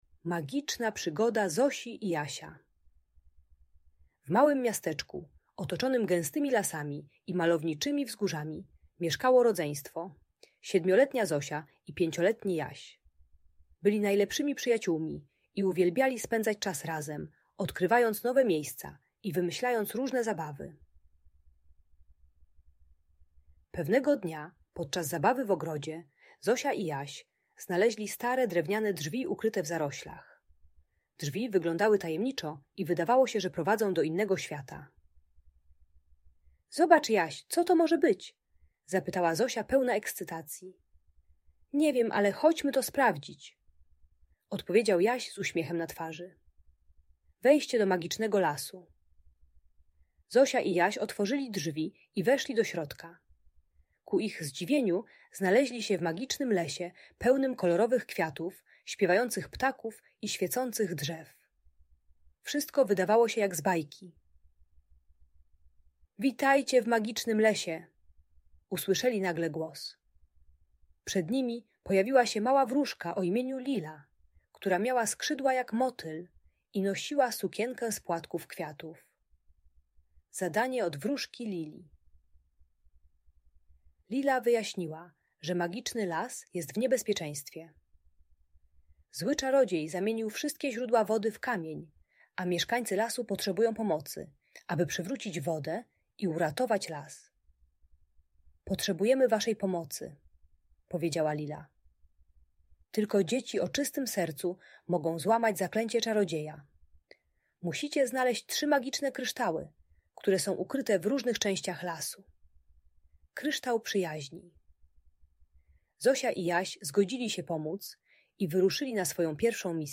Magiczna przygoda Zosi i Jasia - Audiobajka